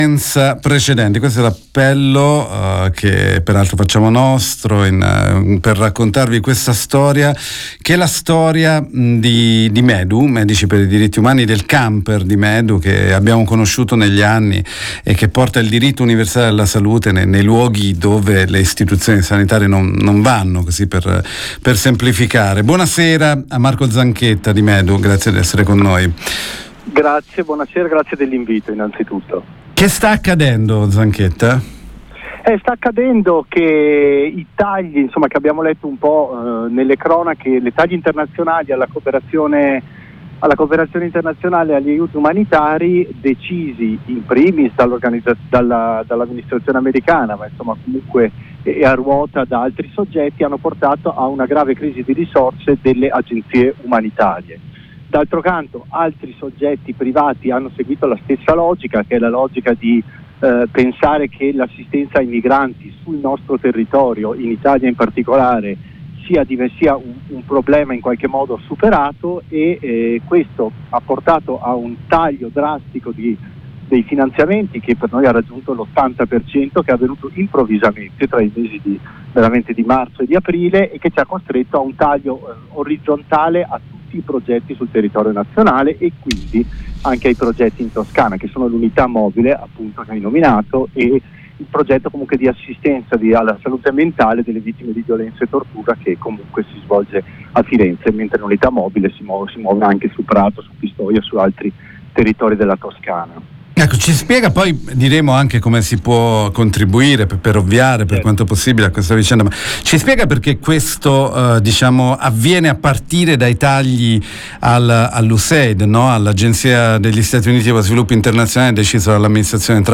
Lo abbiamo intervistato.